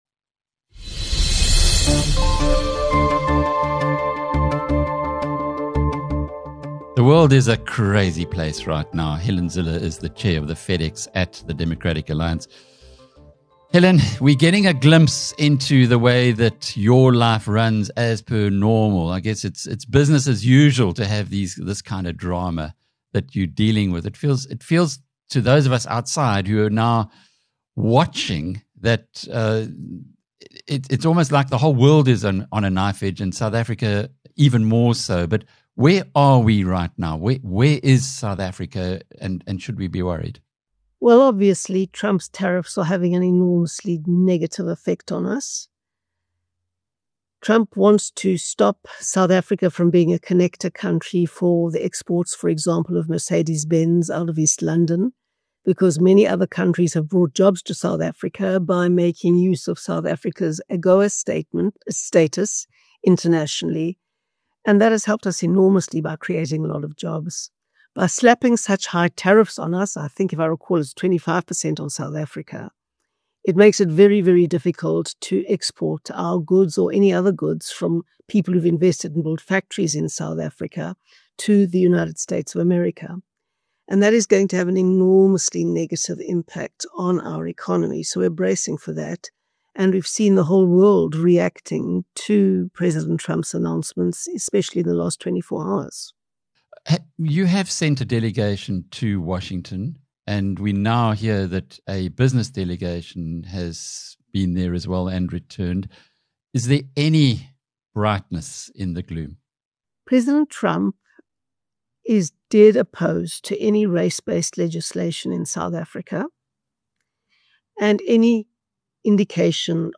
Chair of the Democratic Alliance’s Federal Executive, Helen Zille, shares some Uber Truths in this timeous interview with BizNews editor Alec Hogg - discussing the DA’s view on staying in the GNU (or being ejected from it); the pressure being imposed on the party’s GNU decision by Big Business; why the DA has approached the courts to block the VAT increase; what to do about the proposed Trump Tariffs - and what South Africans should do about jerking the country out of a 15 year economic coma.